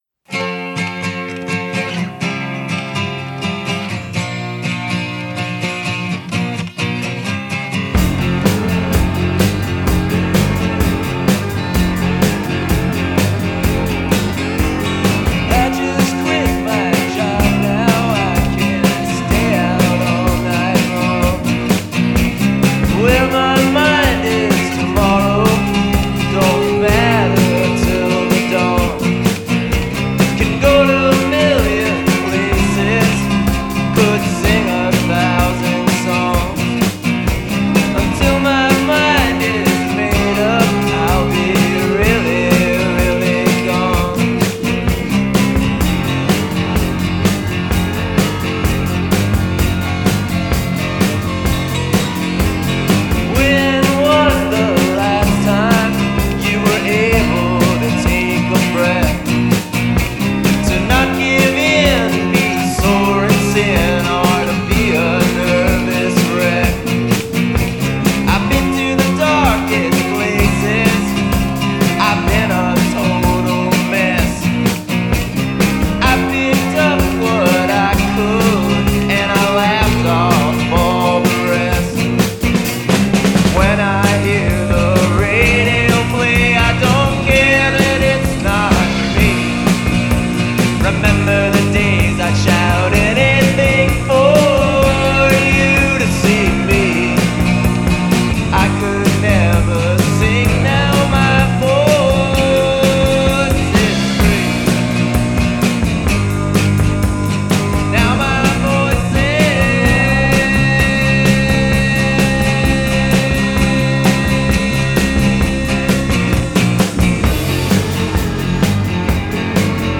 bassist
both on guitar